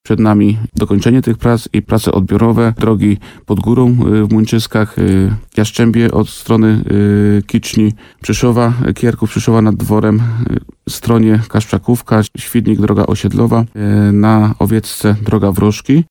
Jak mówi wójt Bogdan Łuczkowski, pierwsze odcinki były wykonane już w ubiegłym roku, a teraz na finiszu jest reszta modernizowanych dróg.